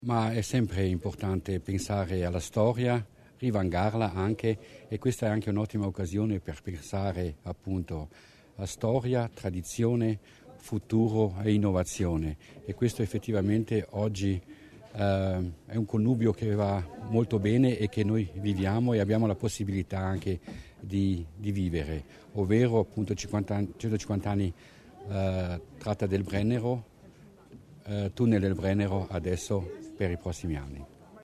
Più treni, tunnel del Virgolo, linea Bolzano-Merano, BBT e tratte di accesso: a Fortezza nella festa dei 150 della Ferrovia del Brennero si guarda al futuro.